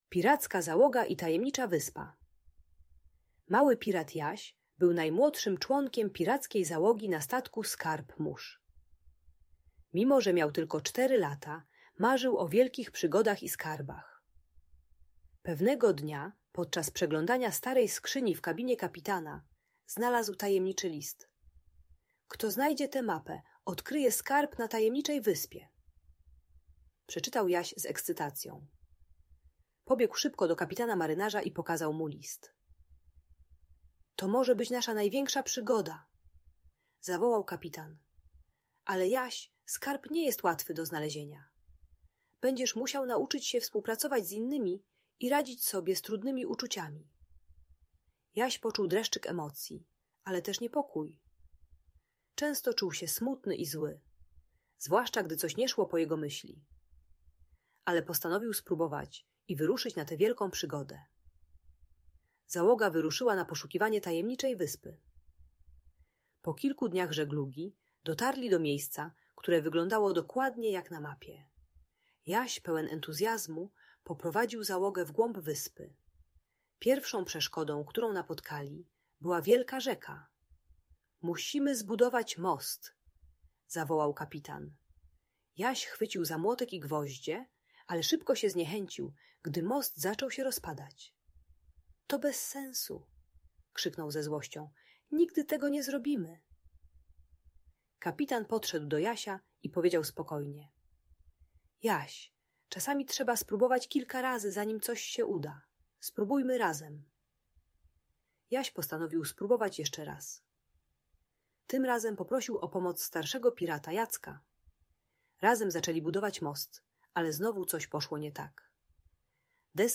Tajemnicza Wyspa - Agresja do rodziców | Audiobajka